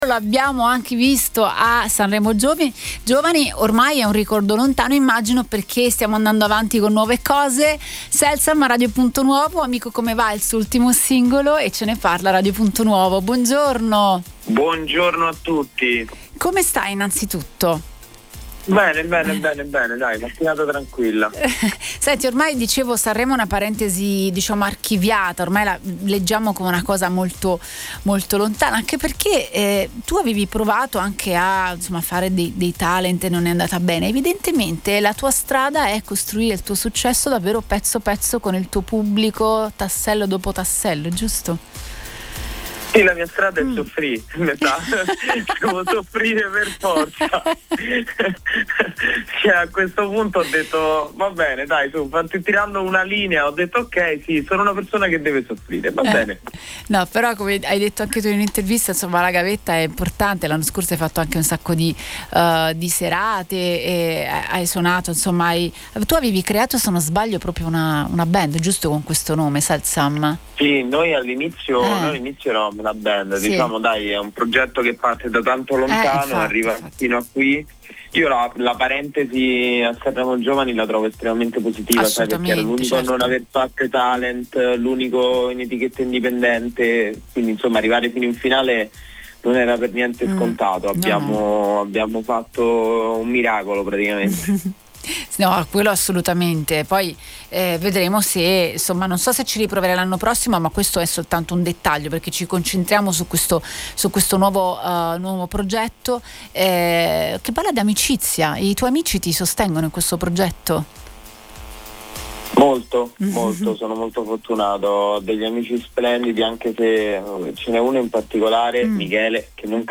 Ai microfoni di Radio Punto Nuovo, il cantautore ha raccontato come la sua carriera sia un mosaico costruito tassello dopo tassello, tra “miracoli” sanremesi e intuizioni digitali.